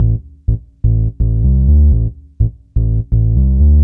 cch_bass_loop_chitown_125_Dm.wav